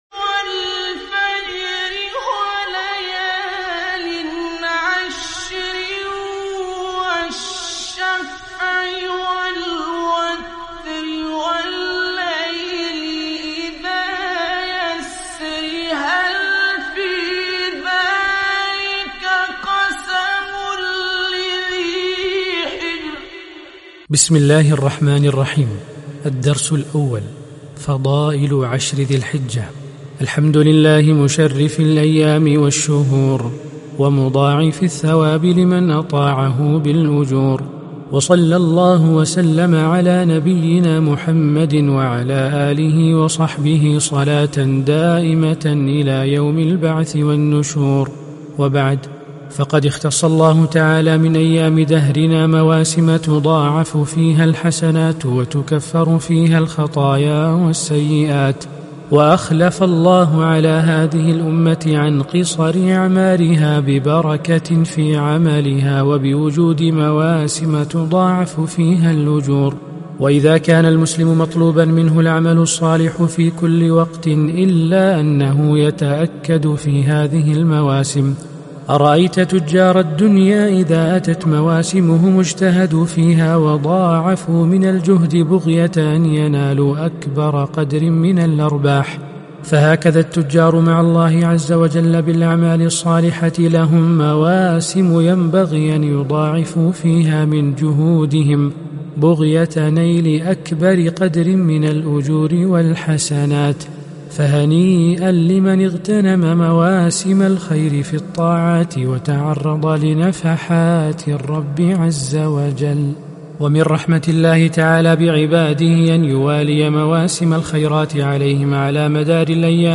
عنوان المادة "(1) القراءة الصوتية لكتاب مجالس عشر ذي الحجة - (الدرس الأول فضائل عشر ذي الحجة)